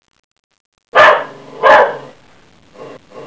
Howl2
HOWL2.wav